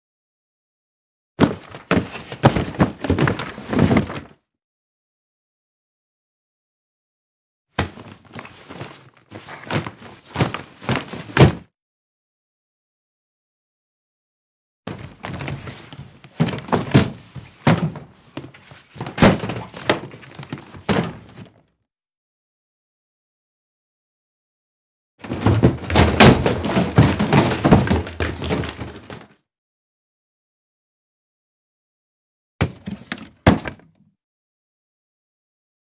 household
Case Luggage Noise 3